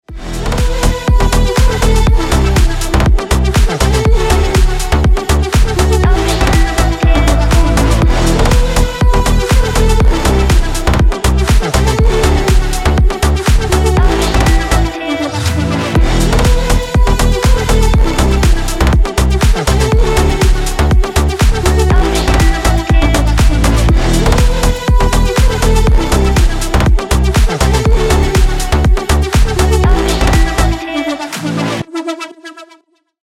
• Качество: 320, Stereo
Electronic
красивый женский голос
Стиль: house